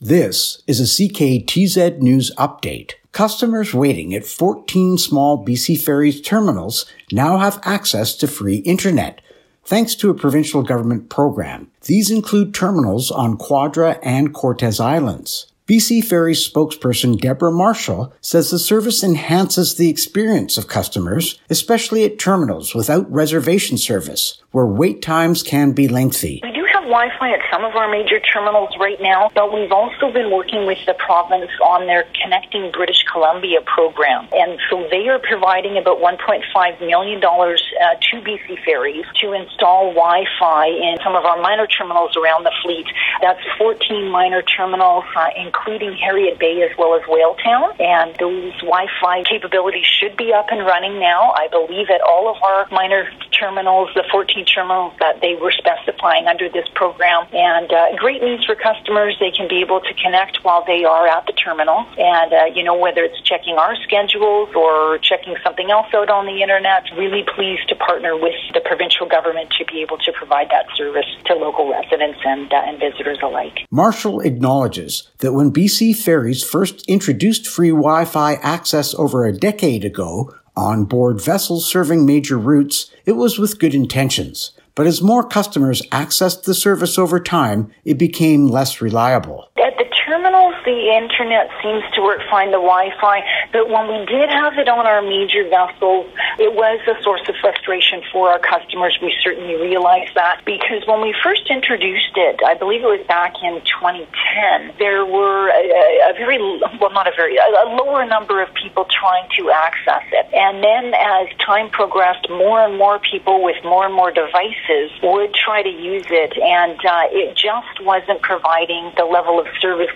CKTZ News report